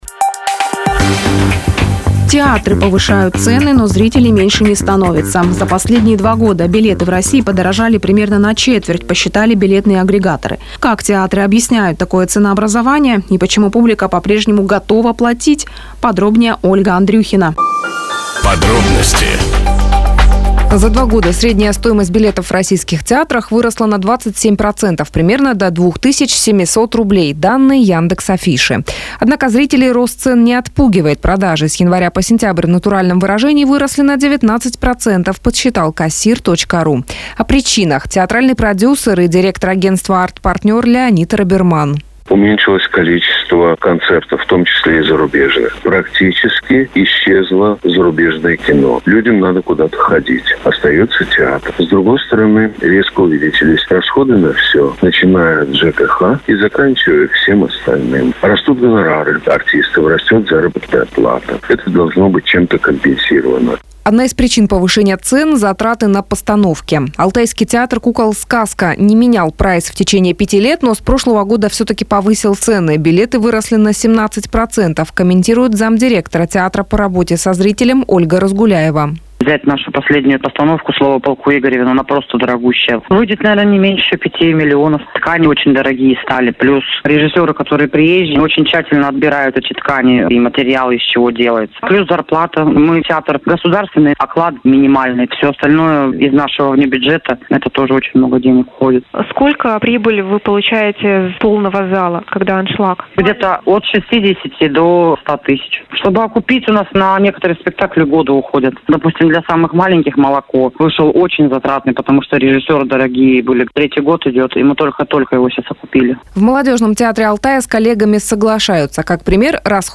Как театры объясняют такое ценообразование и почему публика по-прежнему готова платить, рассказывает радиостанция Business (Бизнес ФМ) Барнаул.